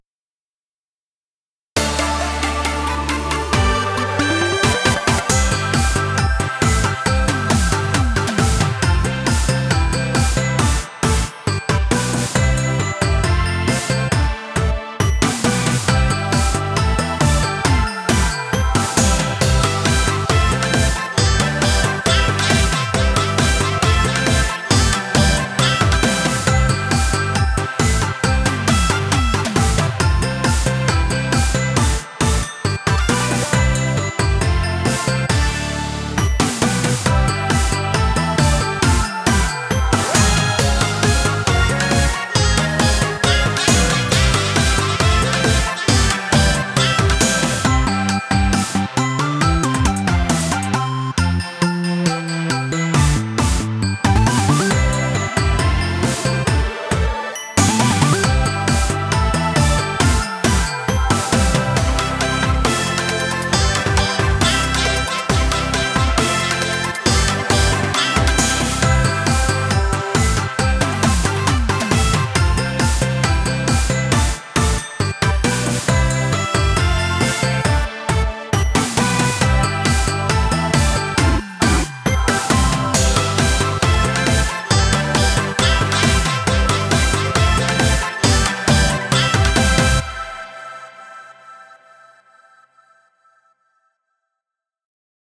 カラオケ
ダンスリミックスバージョン